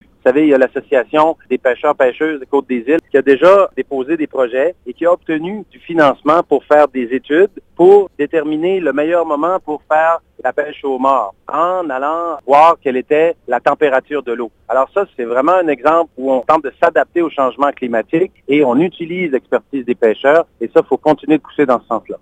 D’ici le scrutin, la salle des nouvelles de CFIM vous présente des entrevues thématiques réalisées chaque semaine avec les candidat(e)s. Les sujets abordés sont les suivants: Environnement (31 mars au 2 avril), Territoire et insularité (7 au 9 avril) et Perspectives économiques (14 au 16 avril).